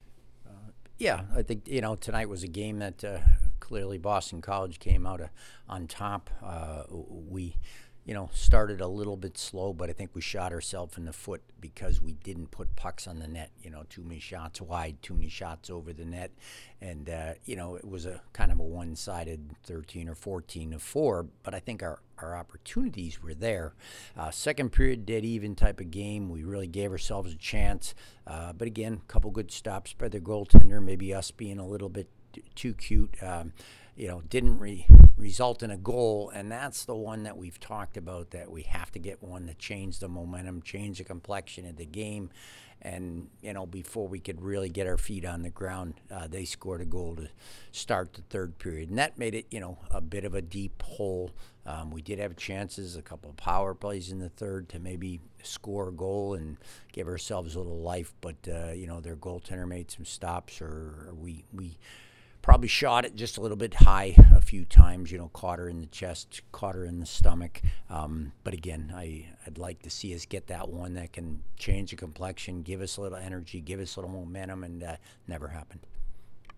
Women's Ice Hockey / BC Postgame Interview